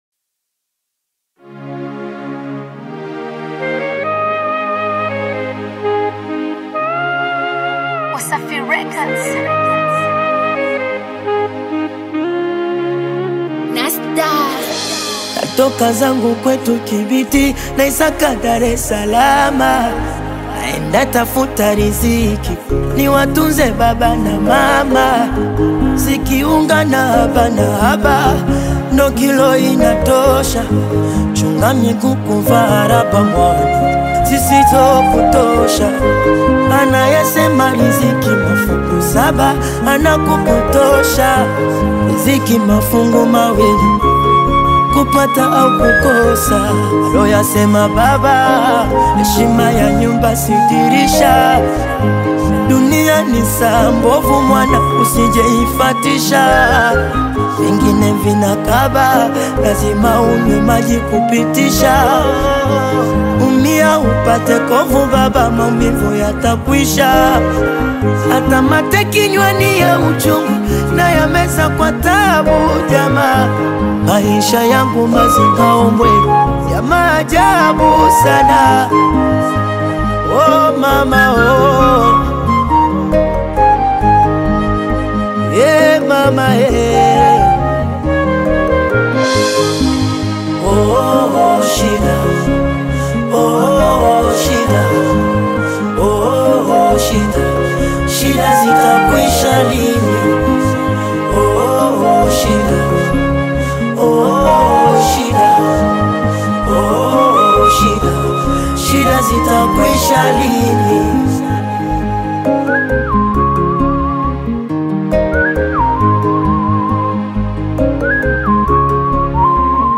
Tanzanian Bongo Fleva